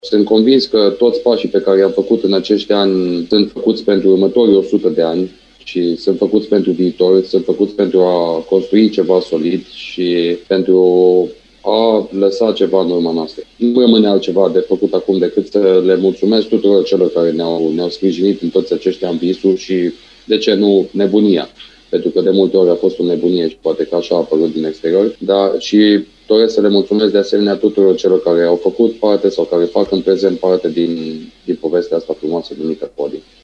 Alb-violeții au marcat astăzi împlinirea a 99 de ani de la înființarea clubului sportiv al Politehnicii Timișoara, printr-un eveniment organizat la Biblioteca UPT.